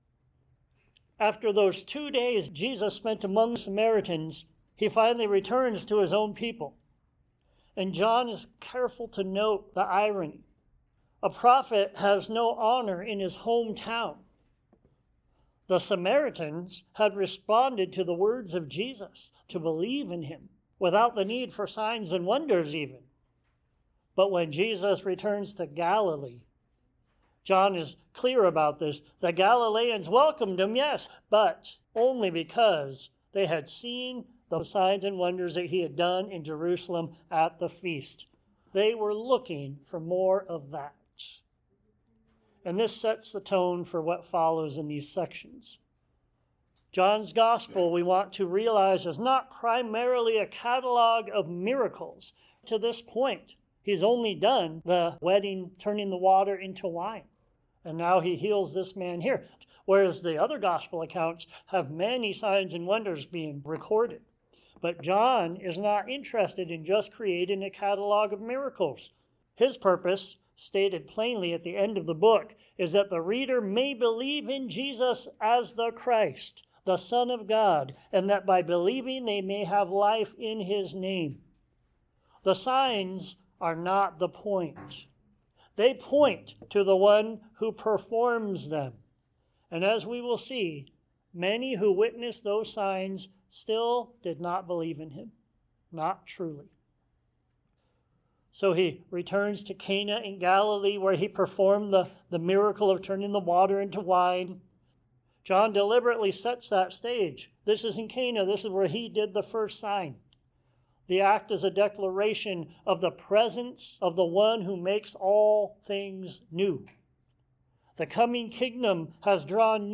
Thy Strong Word John 4:32-5:15 Sermons Share this: Share on X (Opens in new window) X Share on Facebook (Opens in new window) Facebook Like Loading...